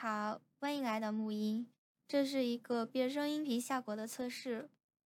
清雨，音色不错的御姐音模型，适合实时变声、聊天。
转换后（通过RVC实时变声）：